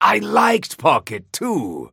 Mo & Krill voice line - I liked Pocket too!